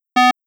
aircraftalarm.wav